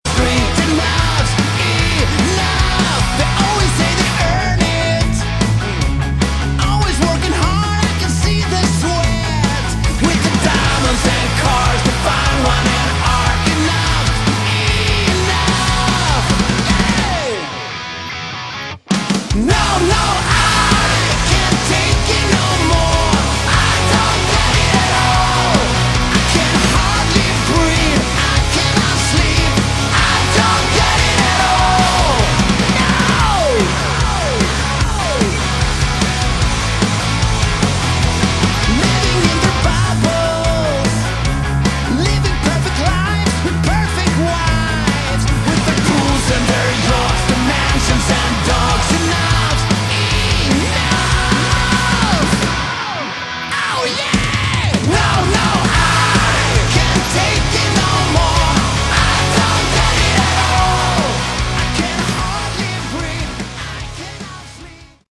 Category: Melodic Hard Rock
vocals
guitars
drums
bass